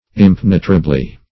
impenetrably - definition of impenetrably - synonyms, pronunciation, spelling from Free Dictionary
Search Result for " impenetrably" : The Collaborative International Dictionary of English v.0.48: Impenetrably \Im*pen"e*tra*bly\, adv.